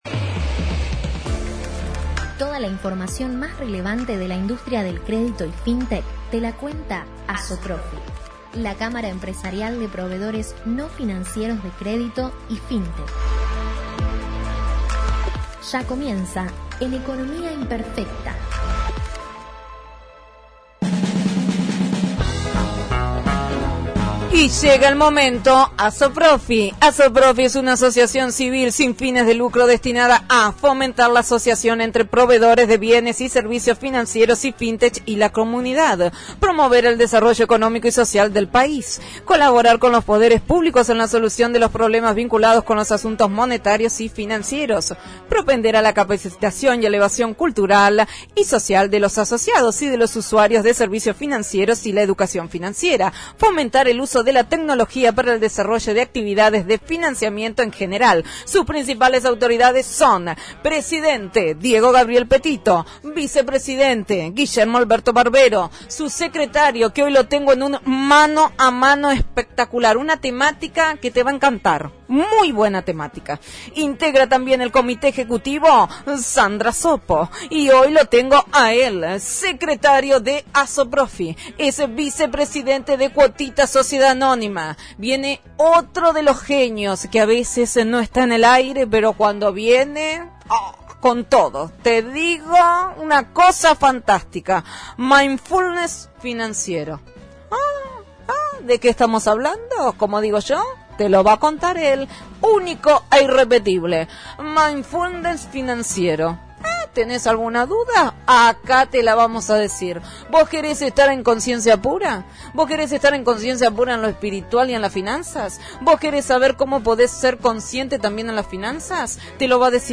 Para volver a escuchar la Columna Radial ingresando aquí: